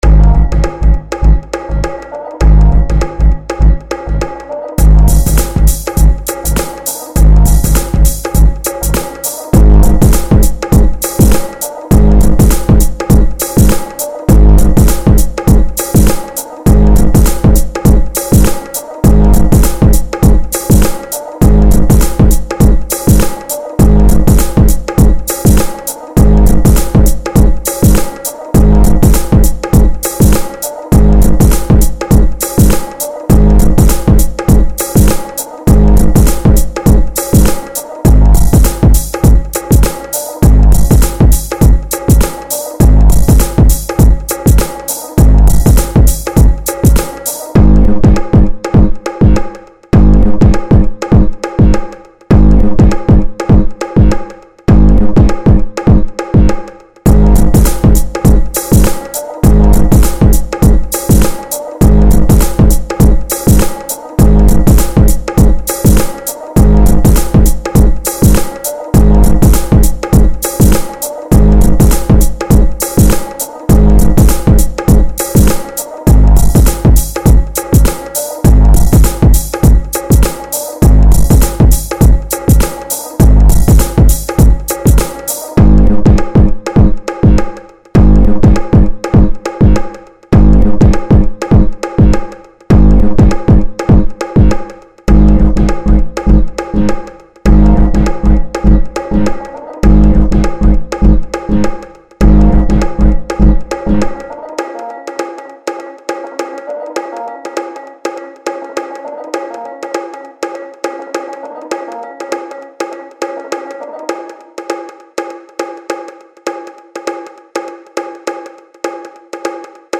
hiphop inst